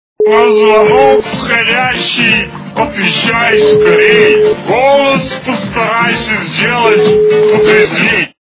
При прослушивании Голос Ельцина - На звонок входящий отвечай скорей... качество понижено и присутствуют гудки.